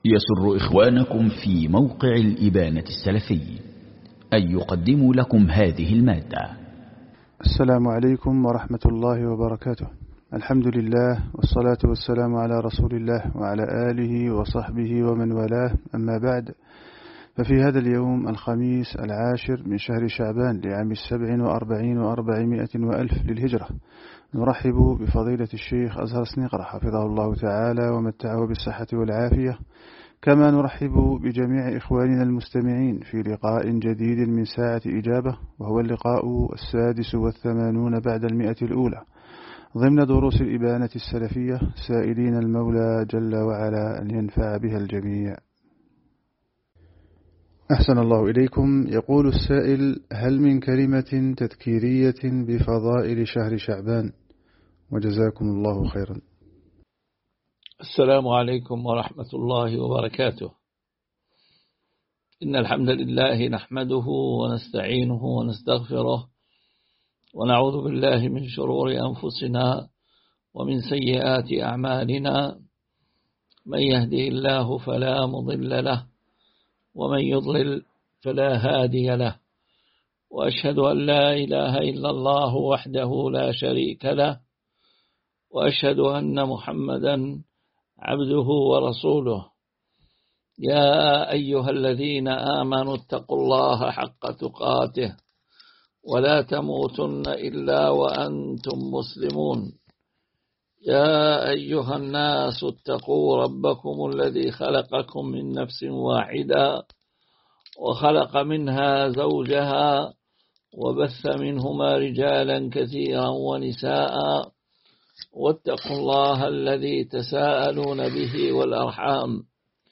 ساعة إجابة عن الأسئلة الواردة